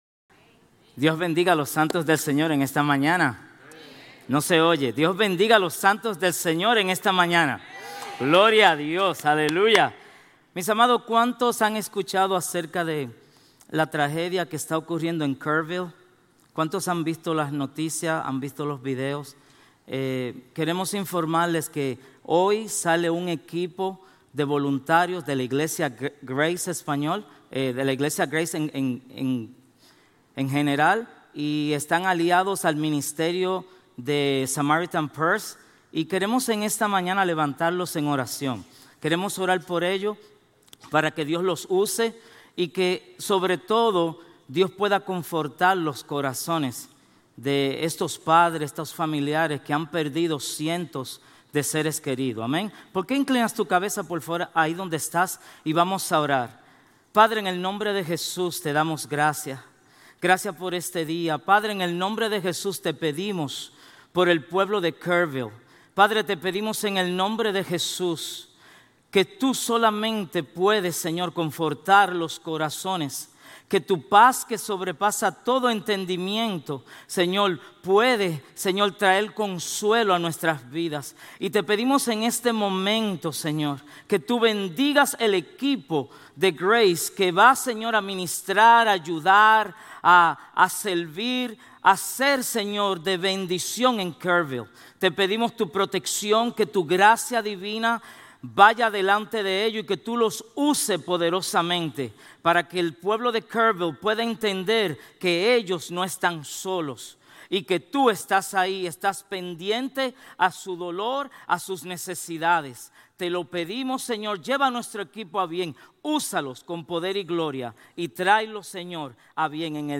Sermones Grace Español 7_13 Grace Espanol Campus Jul 14 2025 | 00:38:31 1x 00:00 / 00:38:31 Subscribe Share RSS Feed Share Link Embed